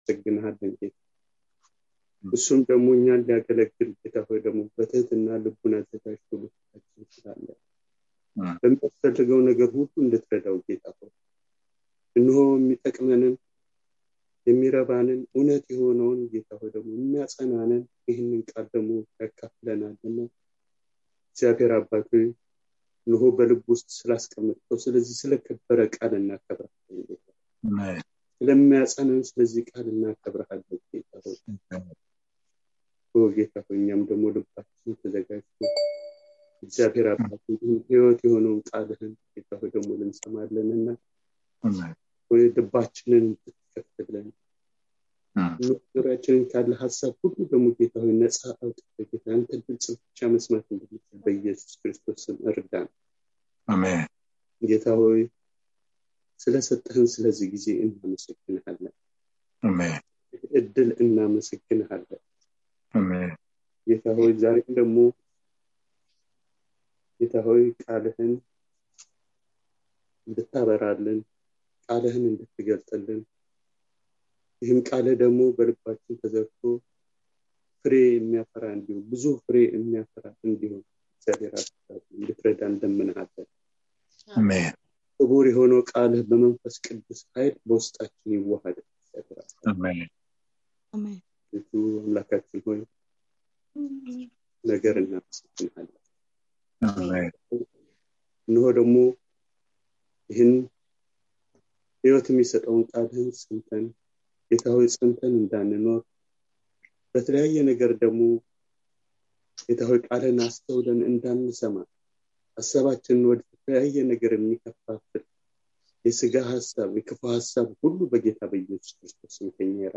Passage: 2ኛ የጴጥሮስ 1: 1-11 (2Pet 1: 1-11) Service Type: Wednesday Evening